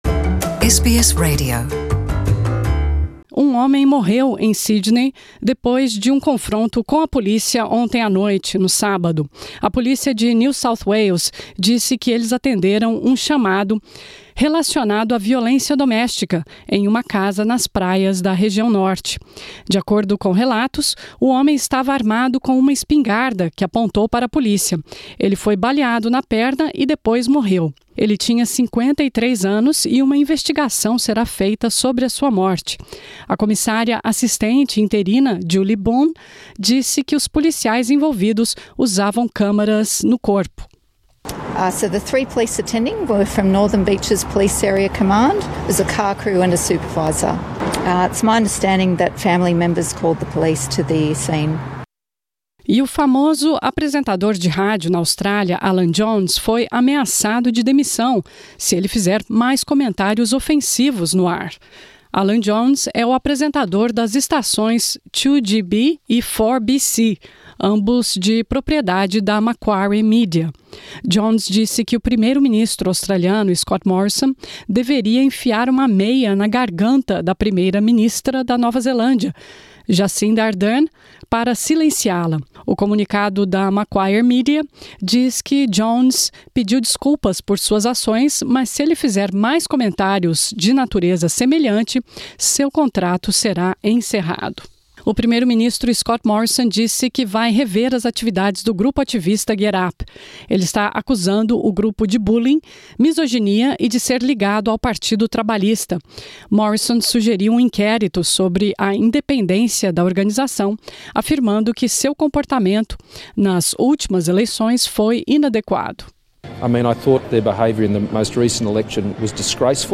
Ouça as notícias mais importantes do dia em português.